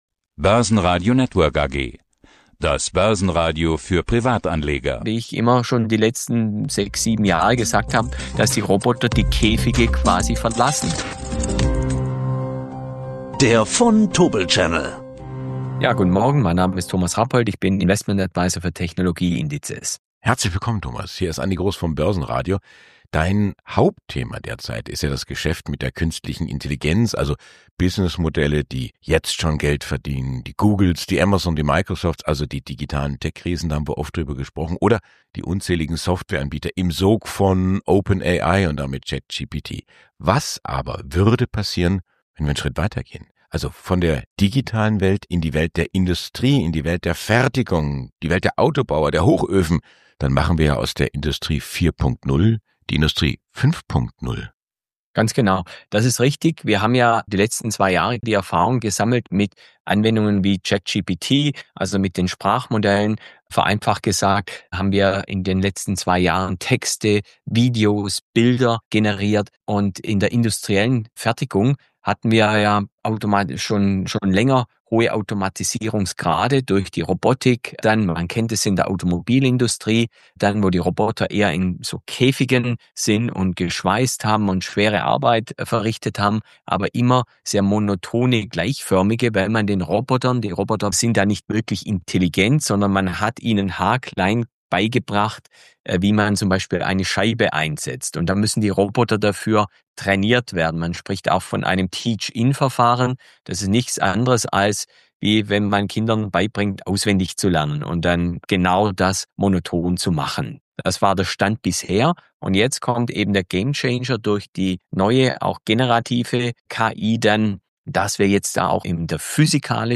Ein Gespräch über Physical AI, smarte Maschinen und riesige Investmentpotenziale.